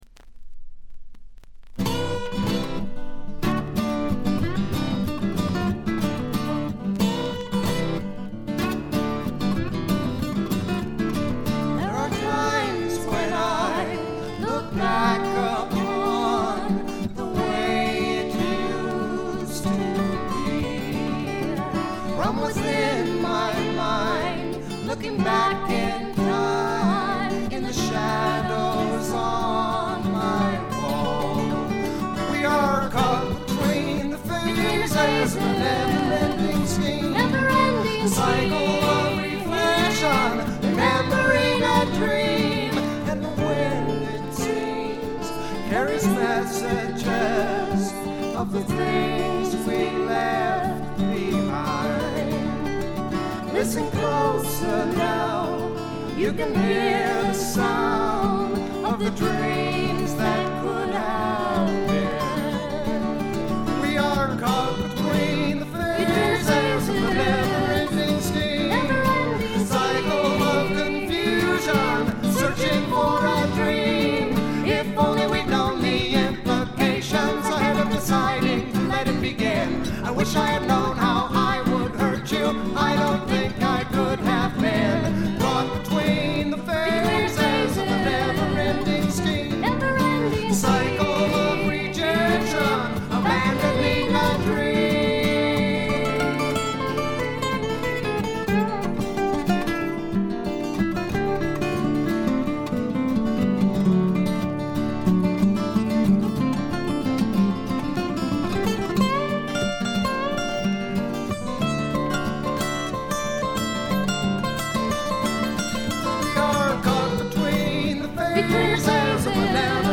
部分試聴ですが、軽微なバックグラウンドノイズにチリプチ少し。
70年代初頭の感覚が強い「あの感じの音」です。
試聴曲は現品からの取り込み音源です。
Fiddle
Flute